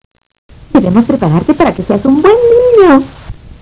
Por motivos de espacion estos sonidos estan hechos en formato wav de 8 bits, por eso es que no tienen mucha calidad, si quieres oir las versiones mas claras, solo Escribeme Y yo te mando los MP3 sin ningun problema.